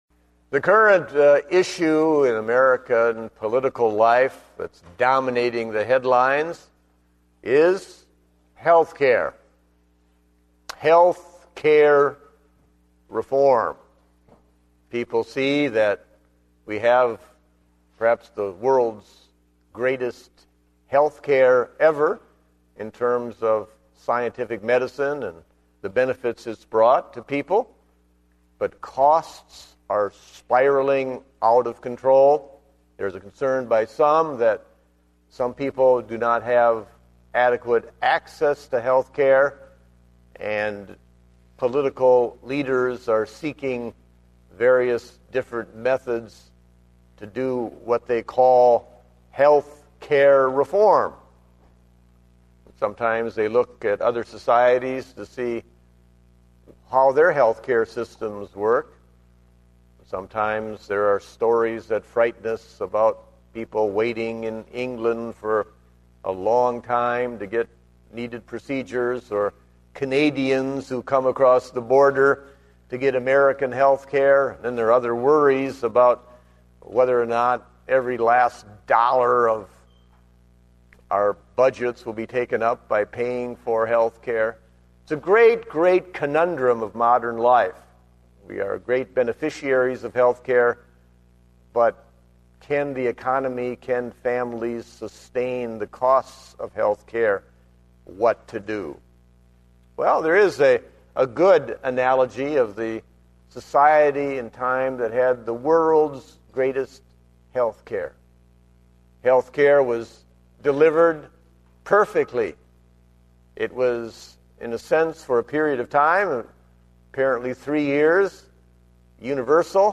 Date: January 17, 2010 (Morning Service)